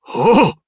Dakaris-Vox_Attack3_jp.wav